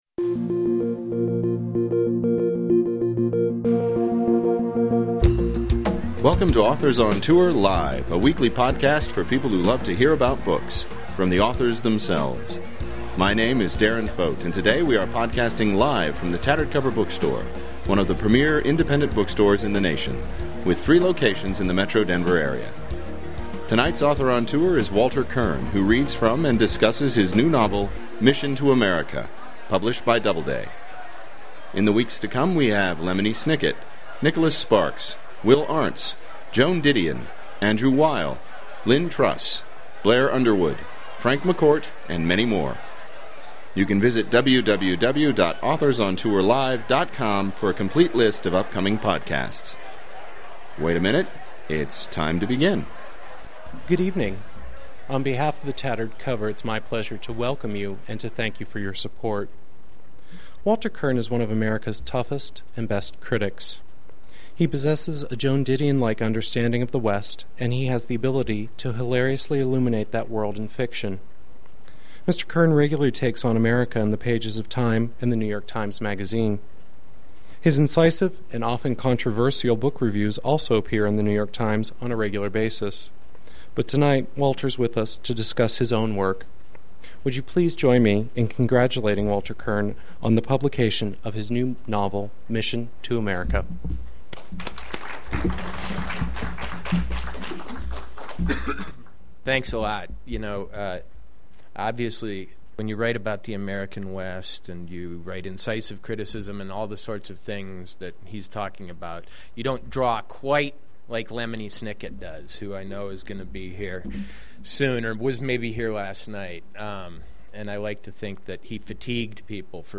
Noted critic and novelist Walter Kirn reads from and discusses his new novel, "Mission to America," a superb story about the collision between the forces of faith and the spiritually empty in America.